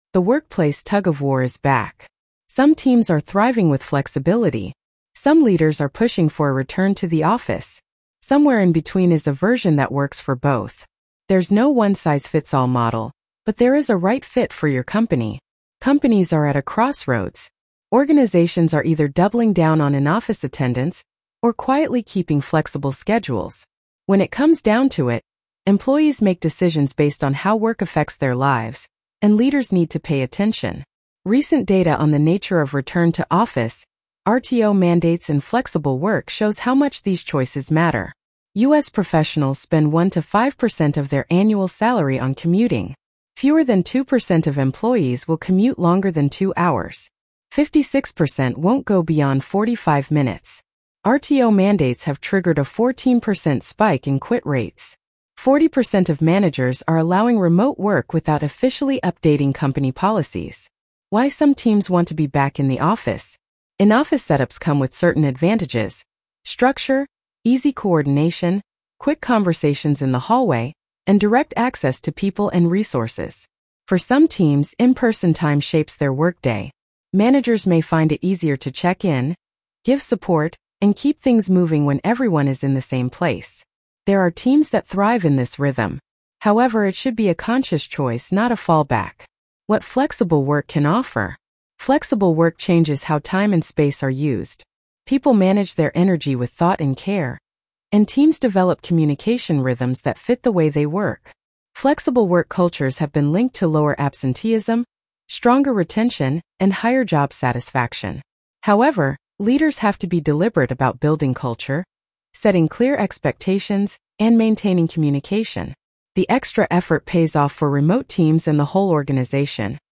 Return to Office or Stay Flexible Blog Narration.mp3